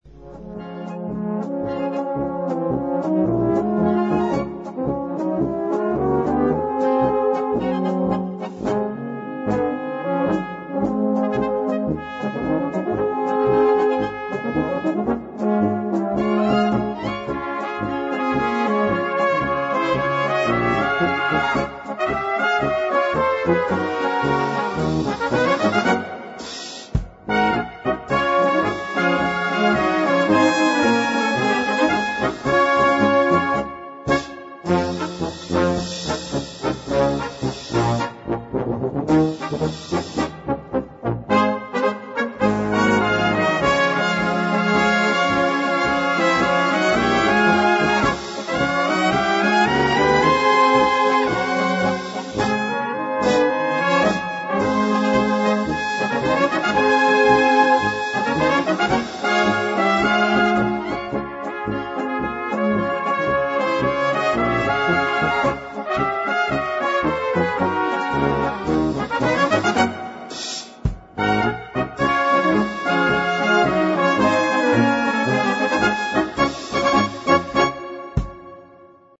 Gattung: Polka
A4 Besetzung: Blasorchester Zu hören auf
das als typisch „BÖHMISCH” bezeichnet werden darf.